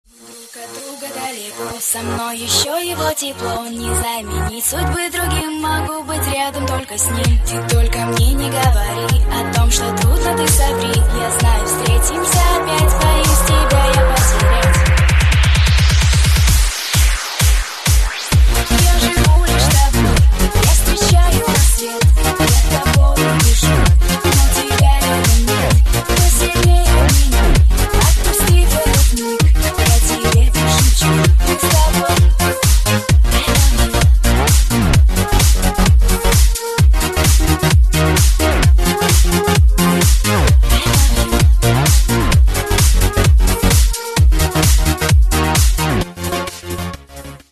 • Качество: 112, Stereo
громкие
Club House